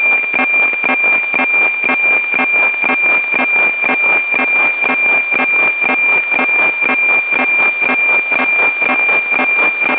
Both use a nominal 2400 Hz AM subcarrier.
The older satellites launched by the Soviet Union and the newer ones launched by the CIS send 120 line per minute IR imagery only. The "tunk" sound is the sync pulses. This sample is from Resurs 01-N4.